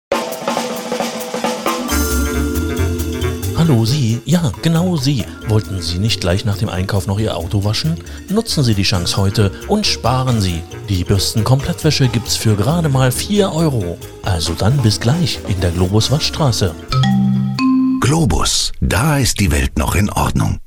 Das sind Werbespots, Hörbücher, Vertonungen und Telefonansagen, die ich im eigenen Studio produziere.